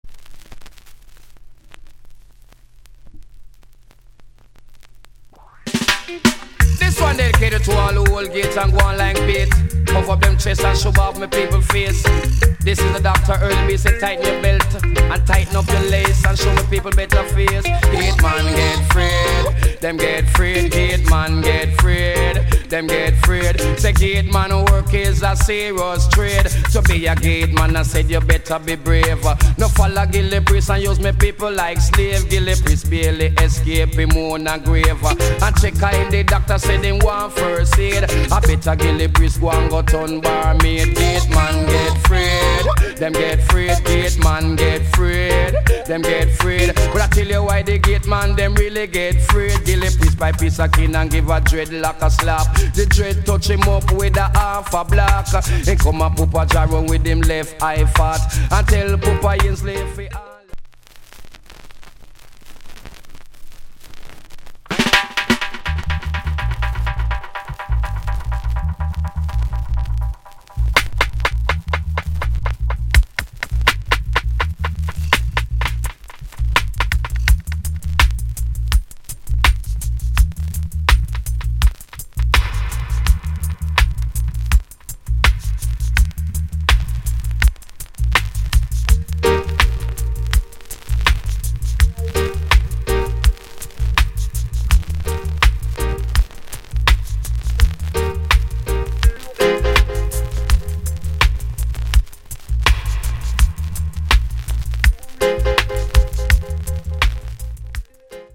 ヴェテランDee JayのHit Tune. ヘリウム声になるところが可笑しい。VersionサイドのDubなかなか良し